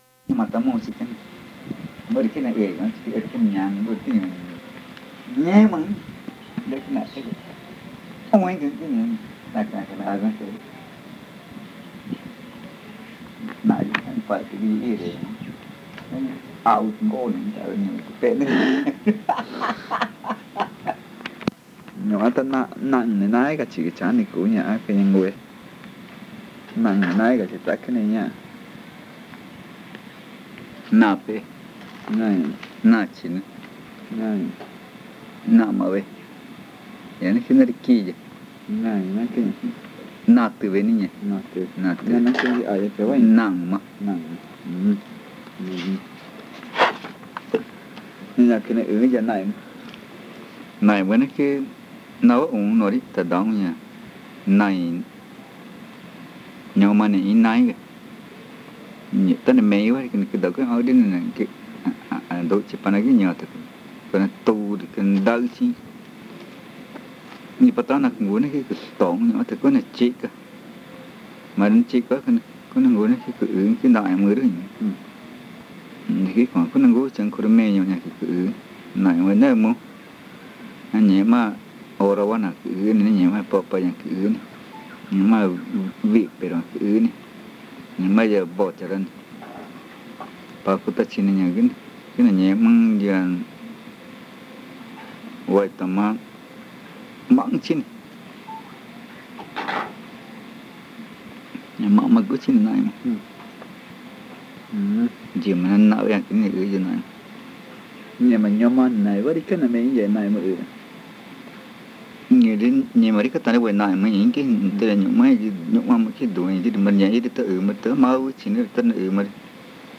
Encuesta léxica y gramatical 2. Arara n°2
Este casete es el segundo de una serie de tres casetes que se grabaron en torno a la variedad magütá hablada en Arara. El audio contiene los lados A y B.